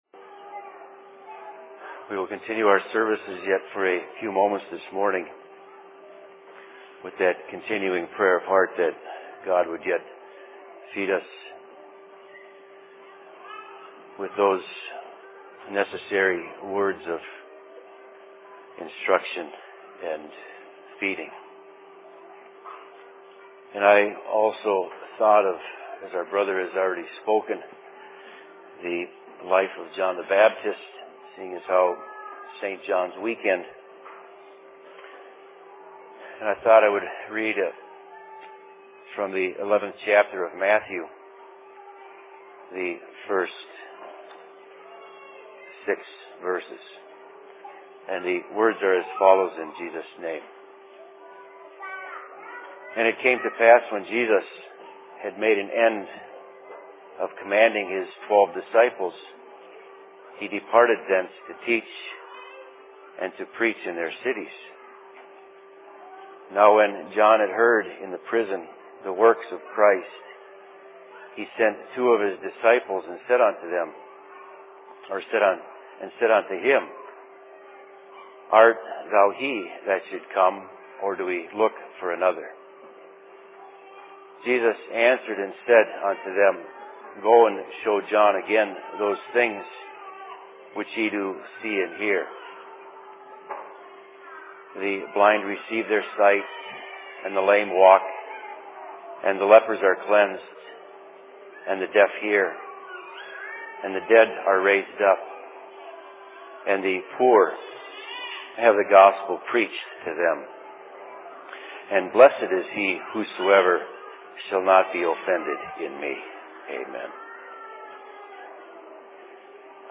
Sermon in Phoenix 24.06.2012
Location: LLC Phoenix